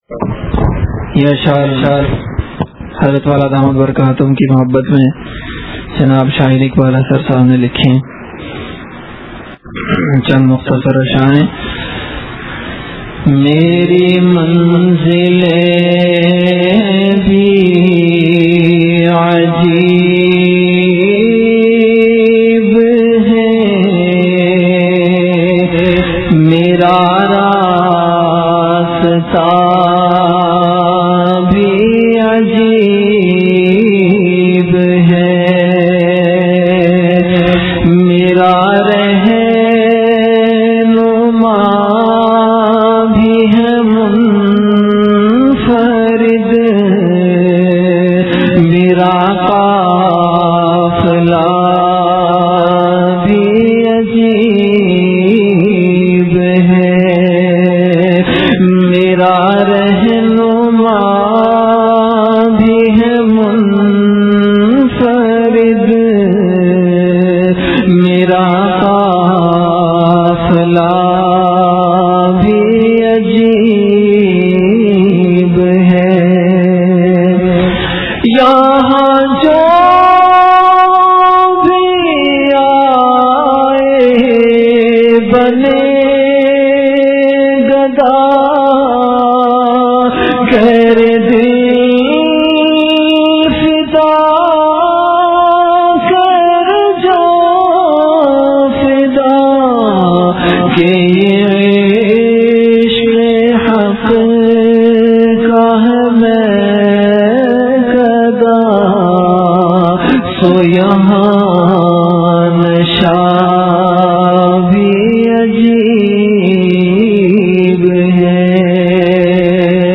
CategoryAshaar
VenueKhanqah Imdadia Ashrafia
Event / TimeAfter Asar Prayer